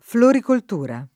floricoltura [ florikolt 2 ra ]